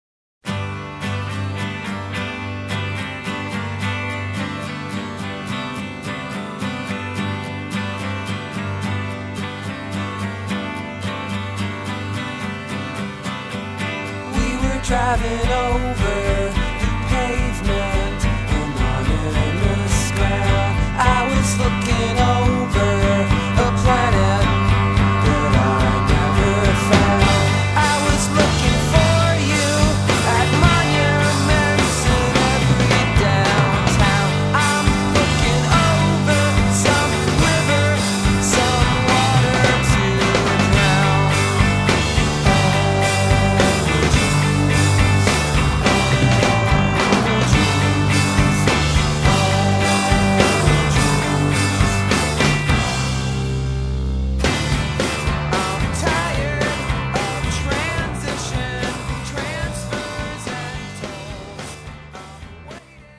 Recorded at Pachyderm Studios, Cannon Falls, MN